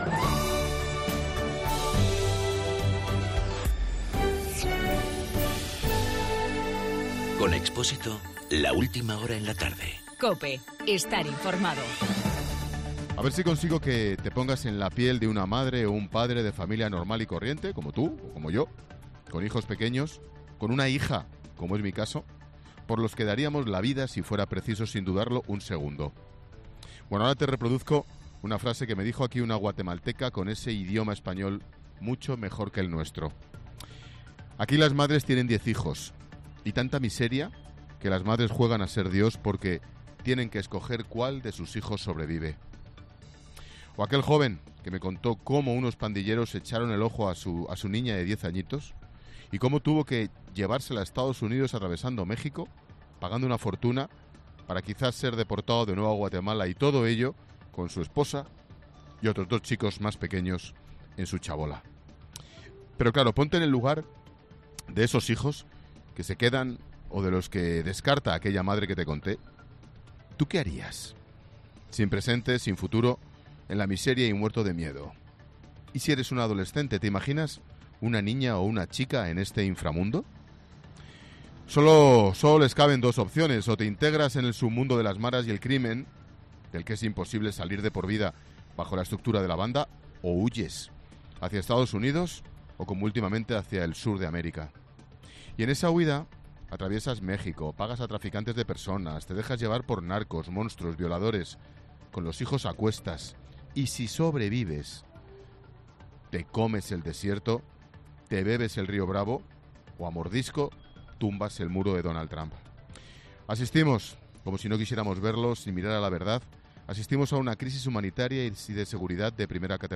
Ángel Expósito analiza en su monólogo de las 18 horas la crisis de Guatemala, en directo desde sus calles.